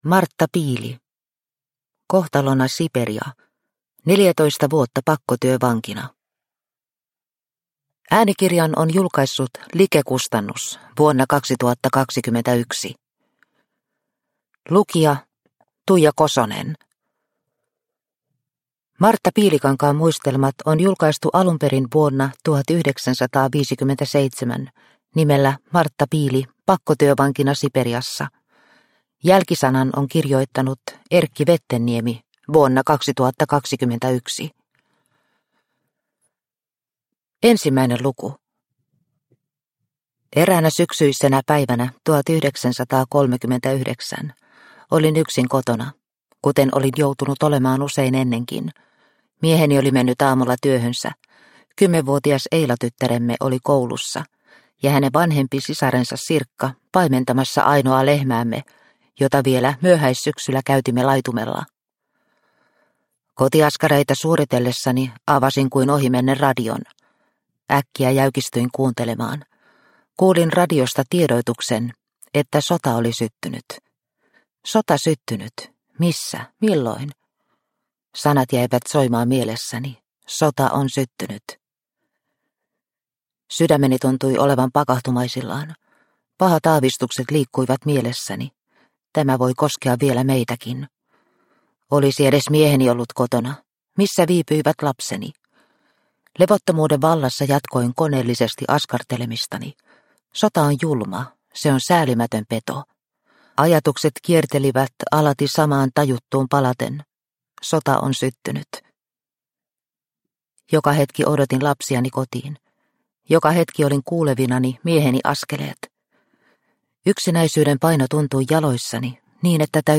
Kohtalona Siperia – Ljudbok – Laddas ner